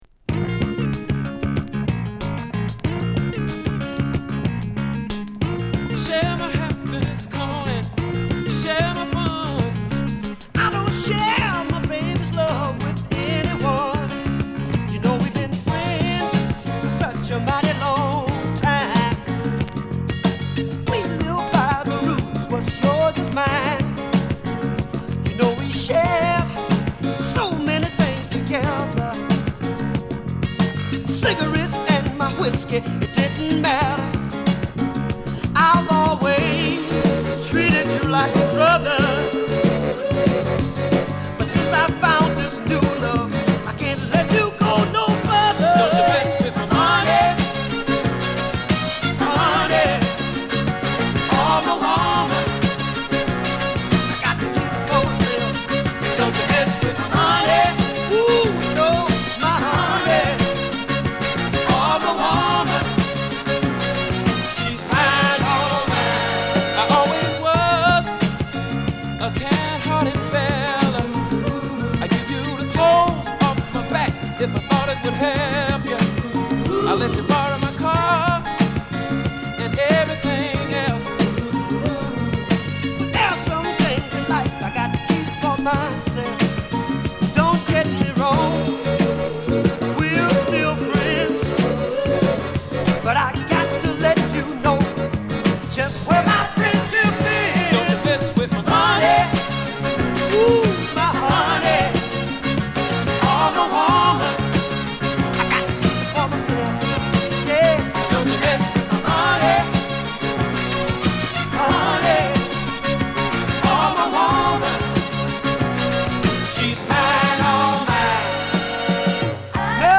土臭くなり過ぎない洗練されたサザン・ソウルと言った本作。